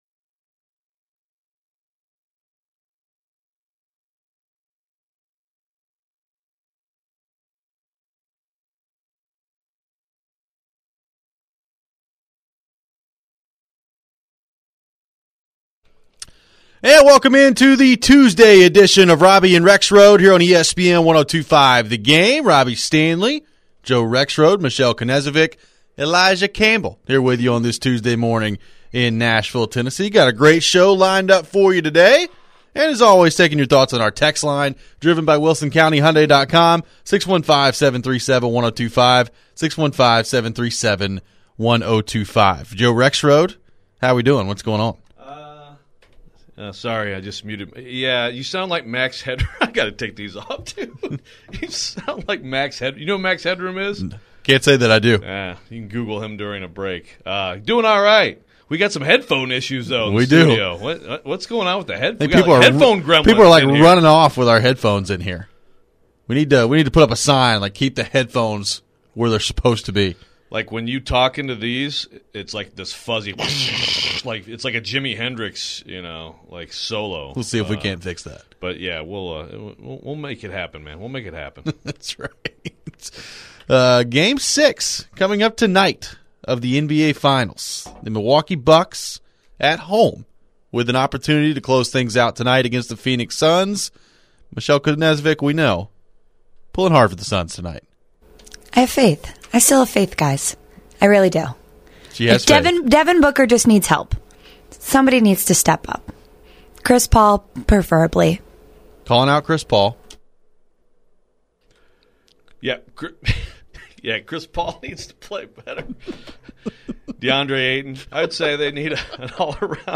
We hear from former Vanderbilt quarterback Jordan Rodgers about his thoughts name, image, and likeness. The two also discuss Vanderbilt football having a better recruiting class than Tennessee.